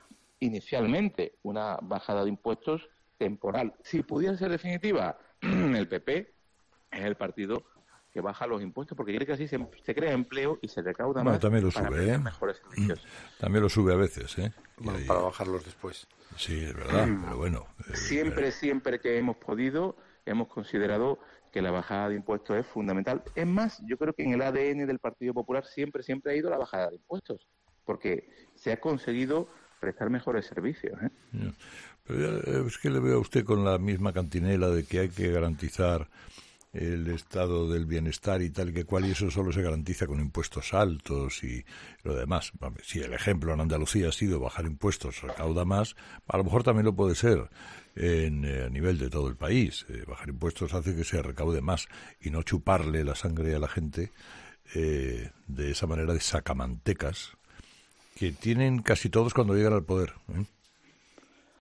La entrevista de Elías Bendodo en 'Herrera en COPE'